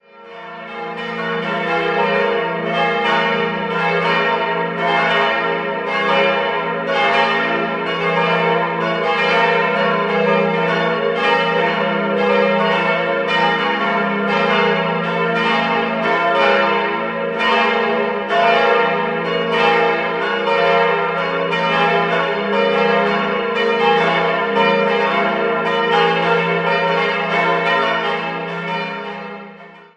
Idealquartett: f'-as'-b'-des'' Die Glocken wurden 1967 von Rudolf Perner in Passau gegossen.